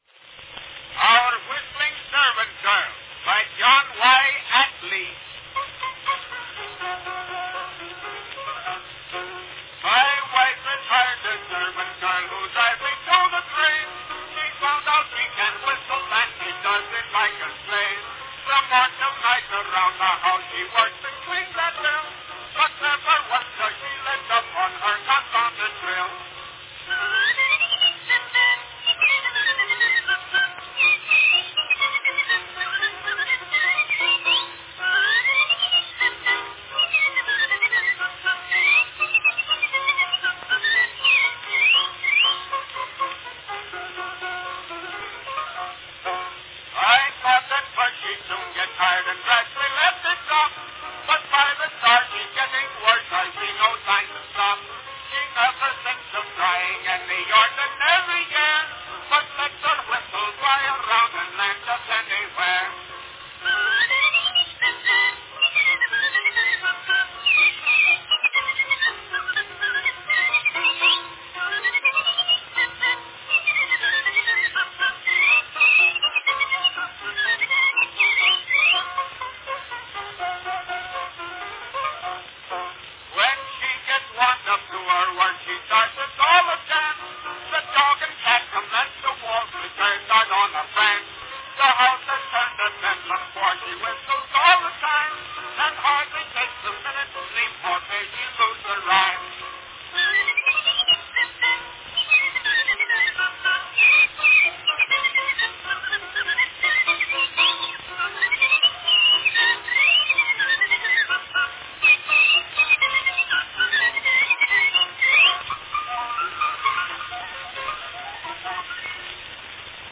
Category Song with whistling chorus
(With Piano Accompaniment.)